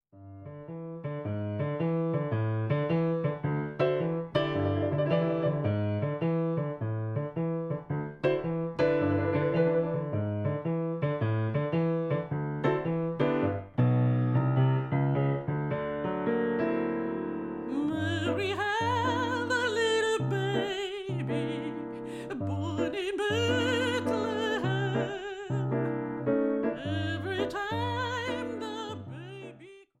Organisten und Keyboarder
Weihnachtstrio